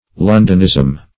Search Result for " londonism" : The Collaborative International Dictionary of English v.0.48: Londonism \Lon"don*ism\, n. A characteristic of Londoners; a mode of speaking peculiar to London.
londonism.mp3